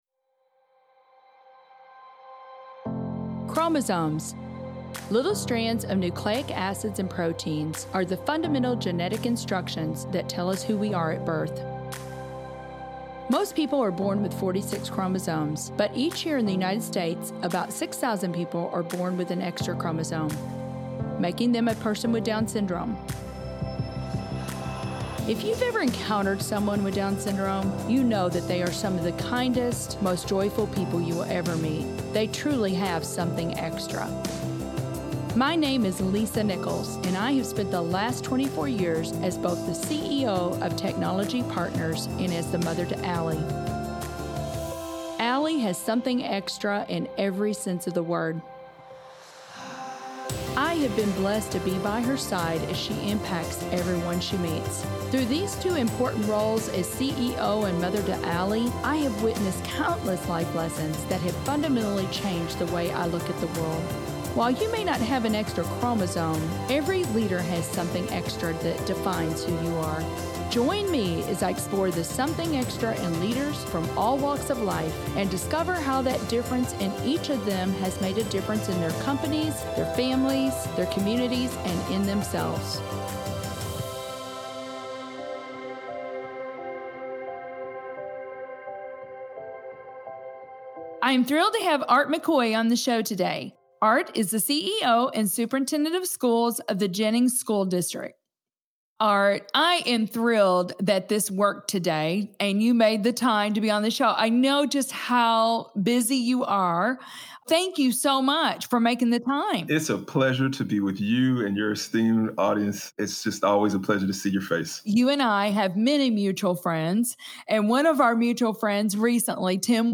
Lisa Nichols, Host